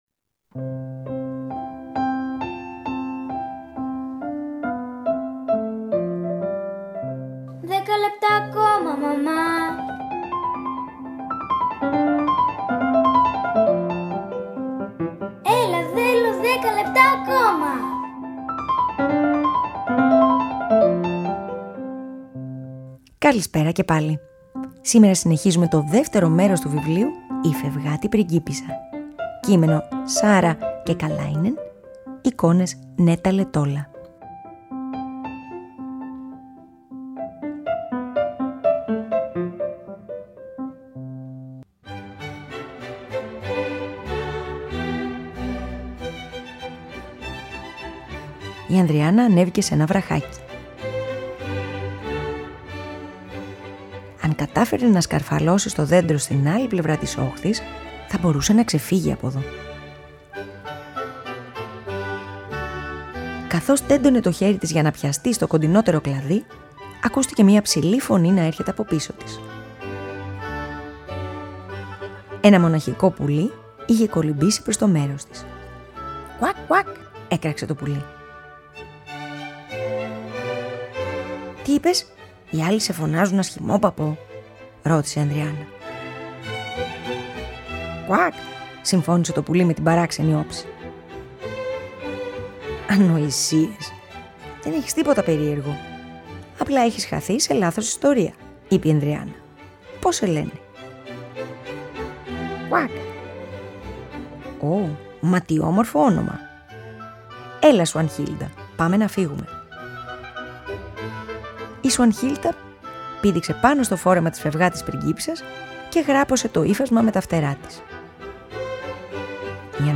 ΠΑΡΑΜΥΘΙΑ